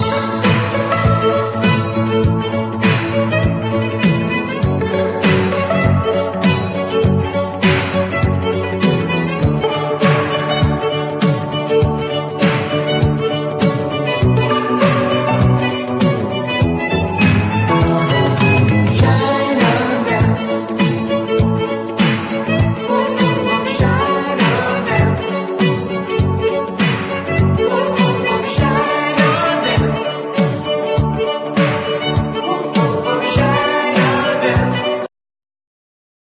Entre le synthé et le violon.